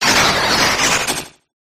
falinks_ambient.ogg